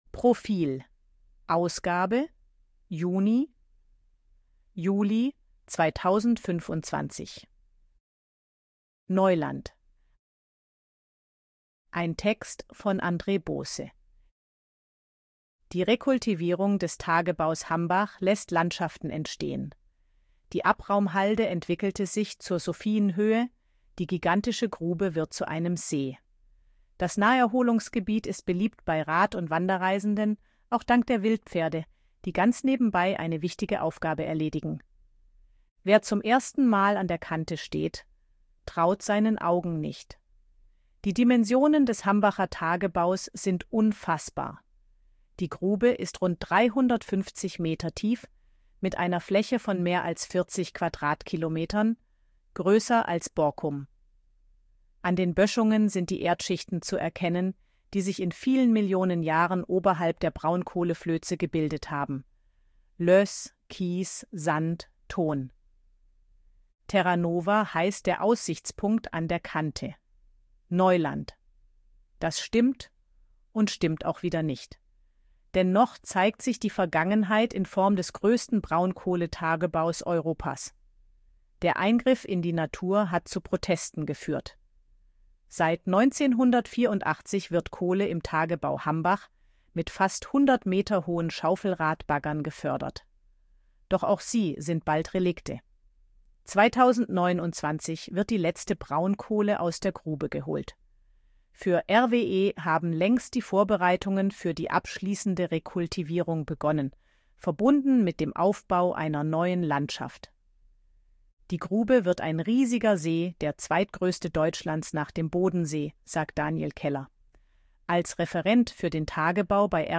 Artikel von KI vorlesen lassen
ElevenLabs_KI_Stimme_Frau_Betriebsausflug.ogg